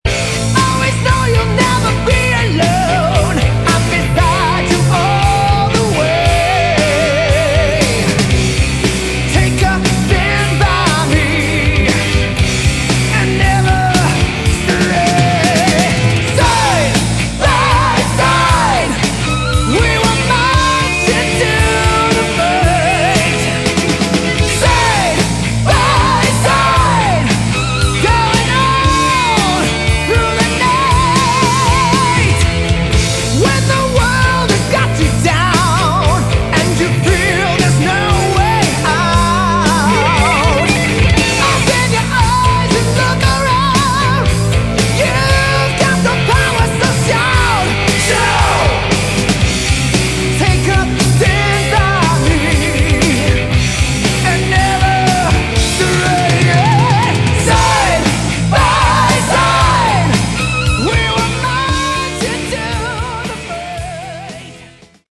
Category: Hard Rock
lead vocals, guitars
keyboards, backing vocals
drums, backing vocals
bass, backing vocals